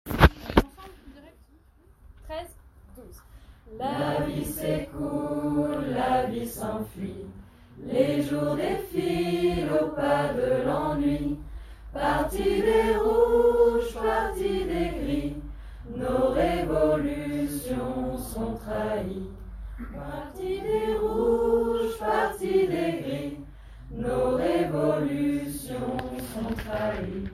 Voix lead :